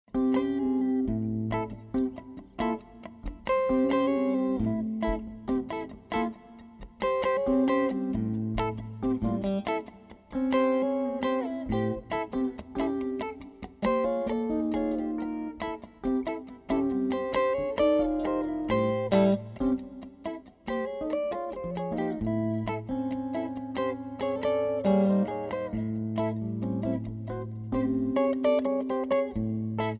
Guitar
Two Electric Guitar Entwine
Lyrical Jazz, Blues and Rock inflected Duets